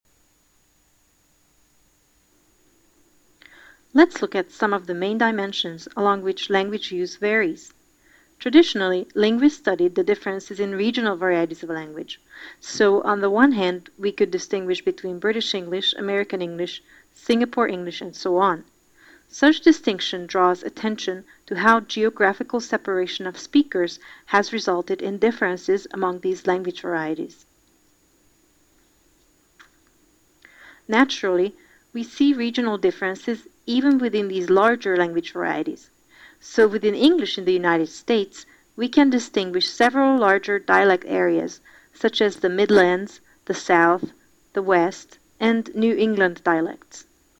Listening comprehension (definition)